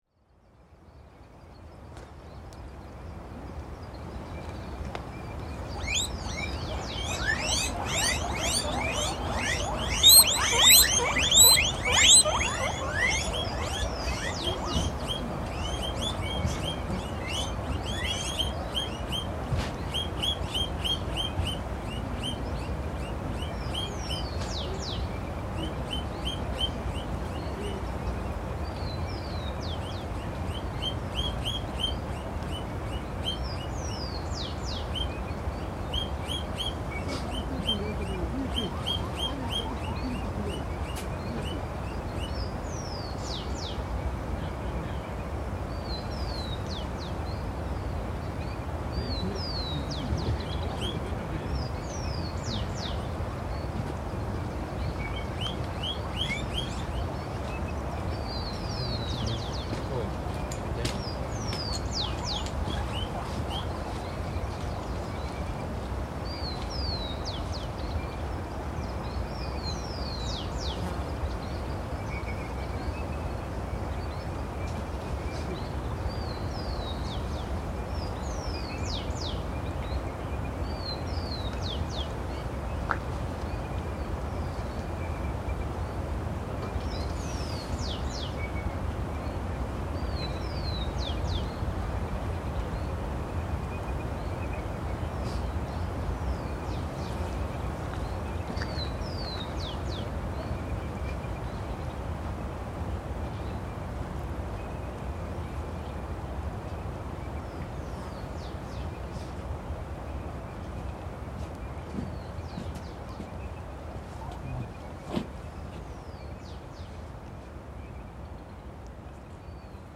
Guinea pigs at Machu Picchu
In the traditional mountain homes surrounding Machu Picchu, guinea pigs are an integral part of life. Their soft chirping fills the air, a domestic melody woven into the cultural fabric of the Andes.